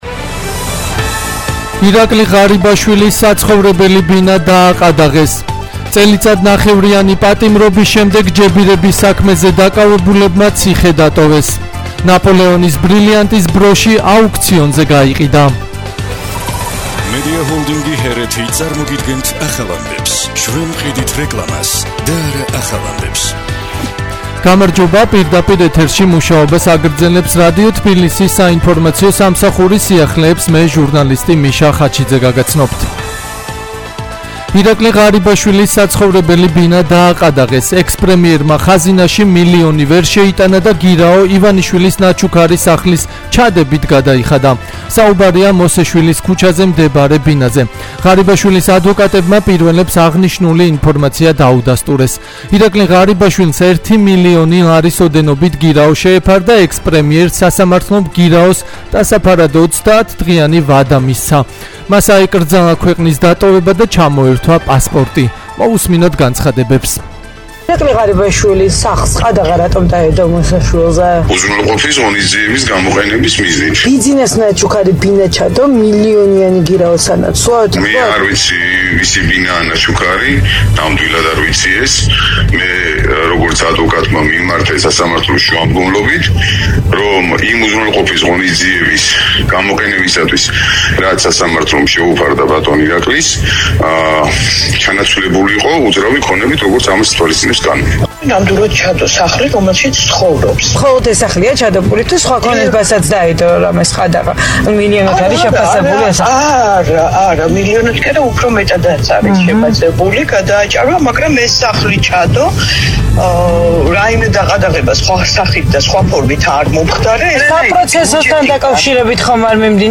ახალი ამბები 12:00 საათზე - HeretiFM